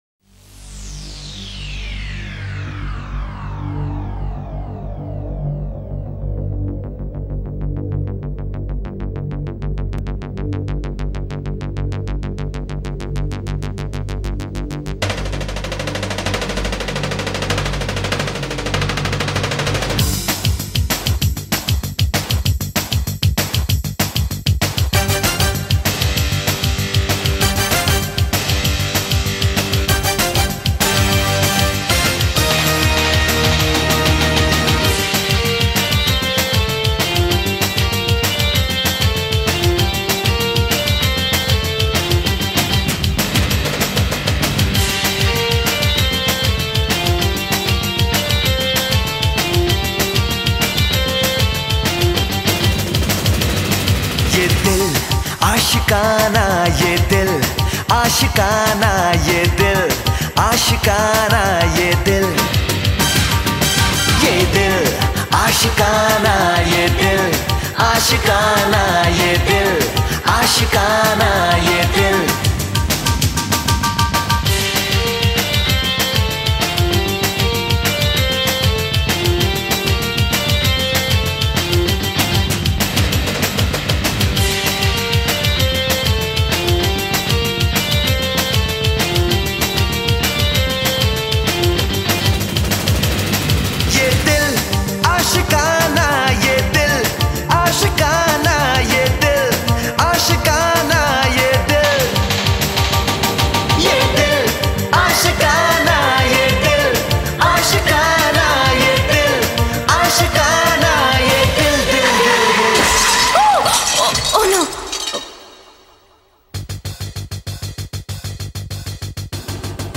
Bollywood Mp3 Songs